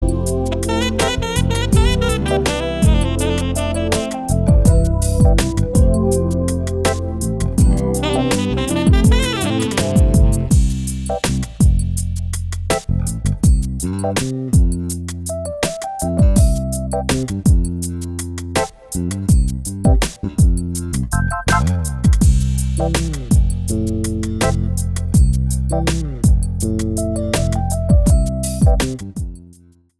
EASY LISTENING  (02.12)